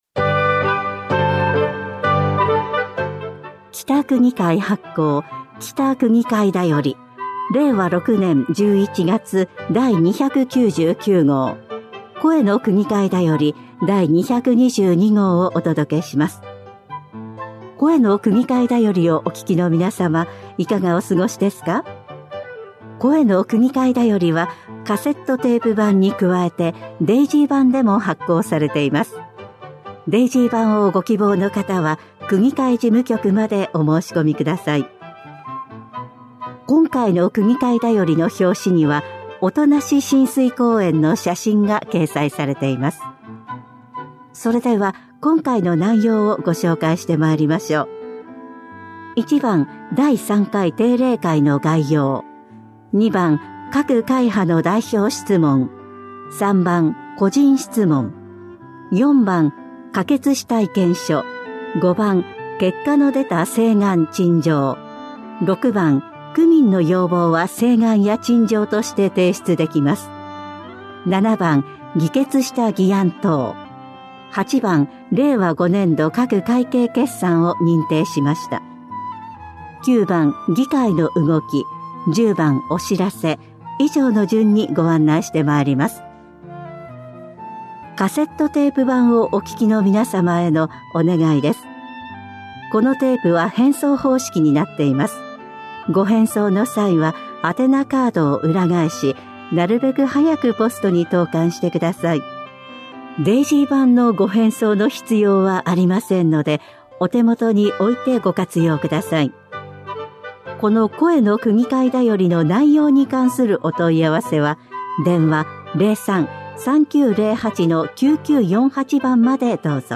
音声データは「きたくぎかいだより」の記事を音声化しています。